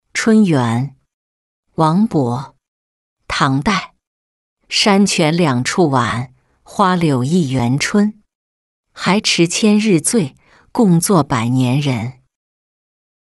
江畔独步寻花·其六-音频朗读